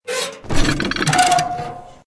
CHQ_GOON_rattle_shake.ogg